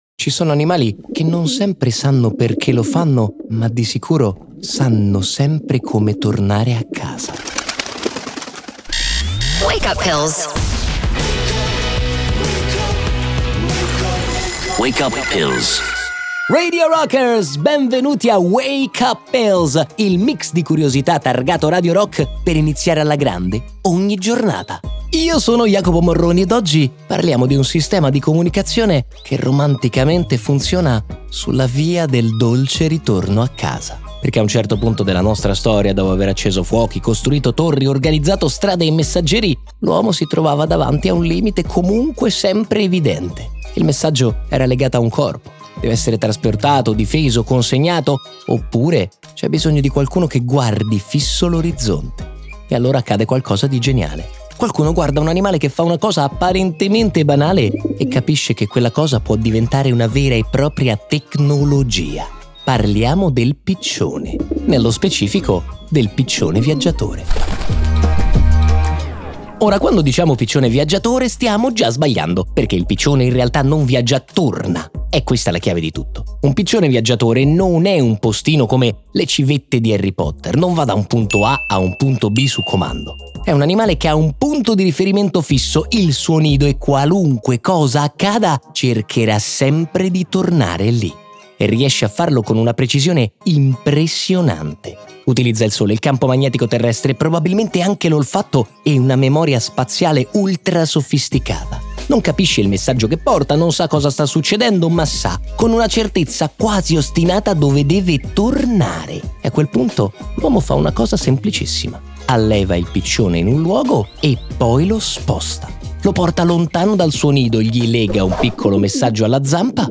Così nasce il sistema di comunicazione più poetico della storia. Wake Up Pills è un podcast Radio Rock Originals.